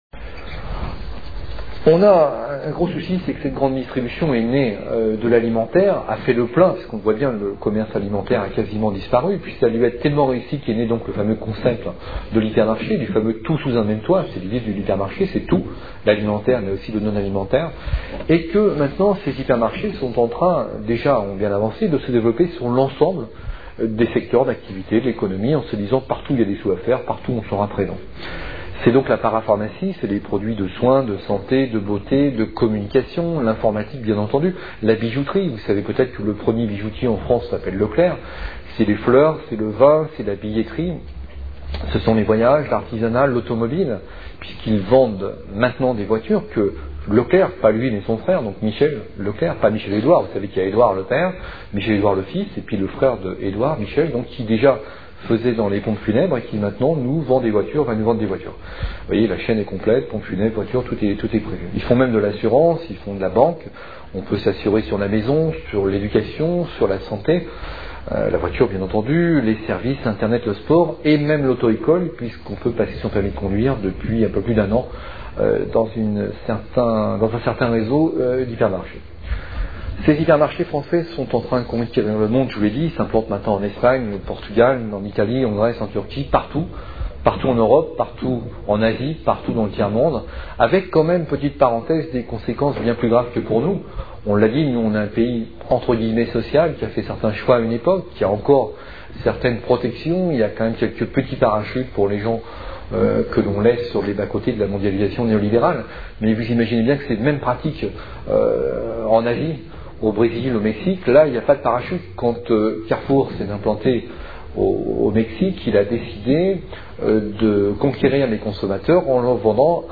Conférence tenue le 18 août 2004 à Montpellier